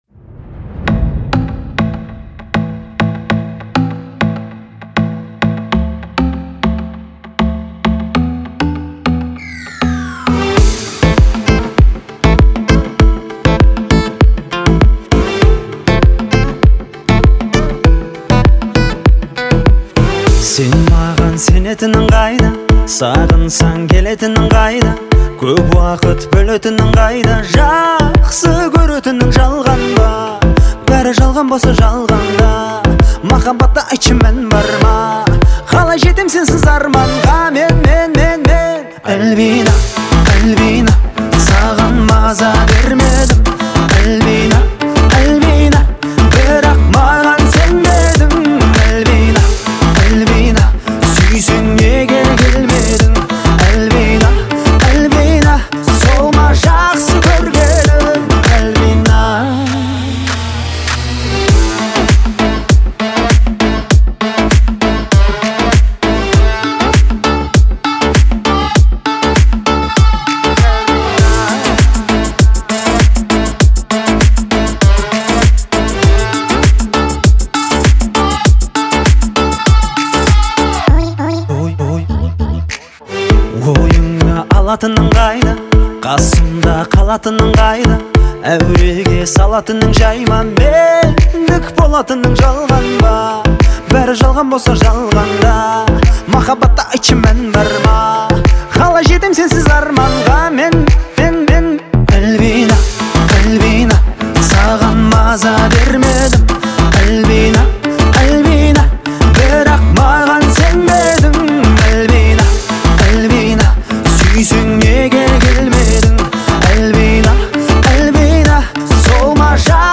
представляет собой яркий пример современного поп-фолка.